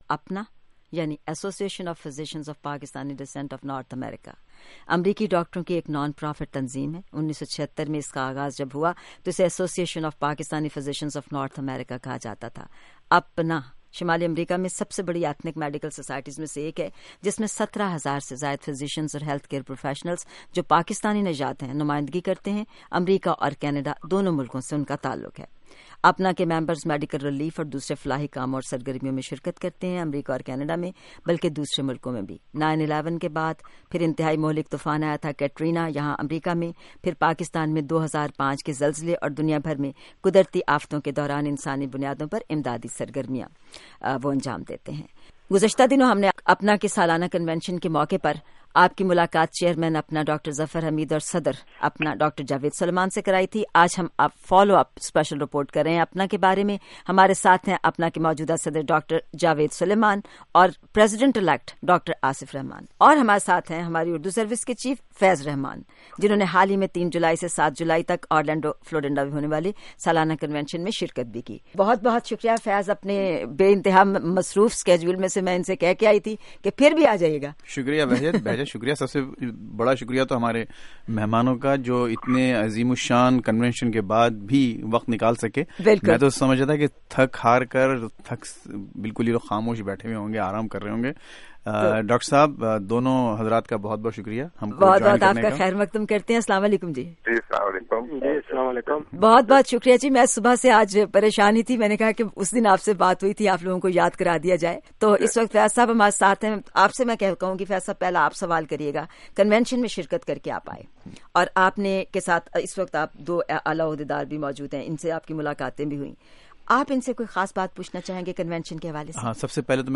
آج کے موضوع: ’اپنا‘ کنویشن، خصوصی انٹرویوز